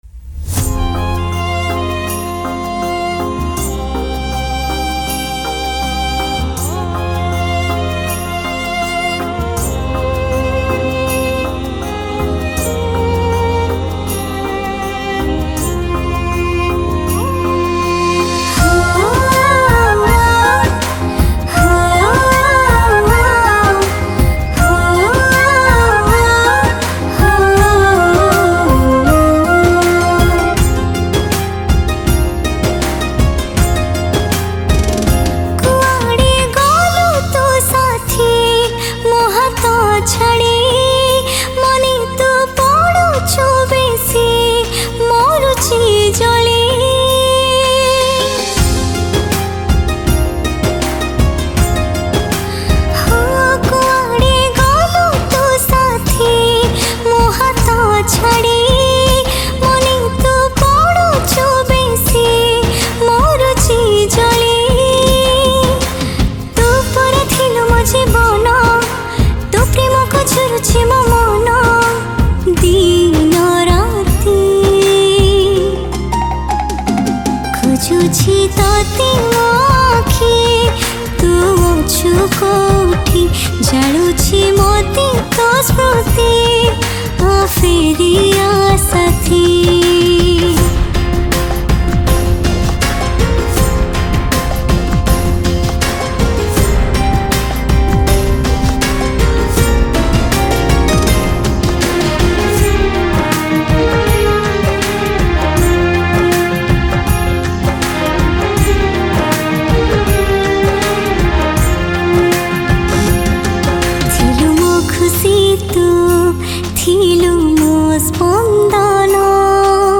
Sad Song Artist
Single Odia Album Song 2022